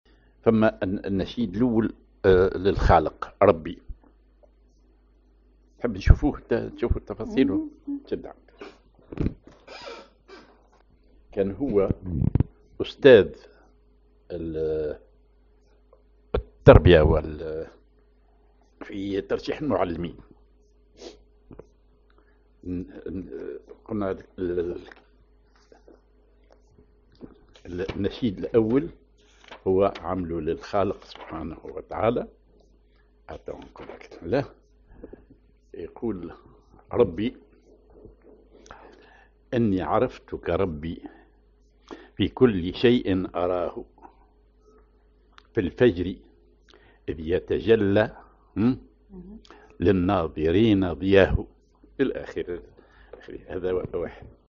Maqam ar دو كبير
genre نشيد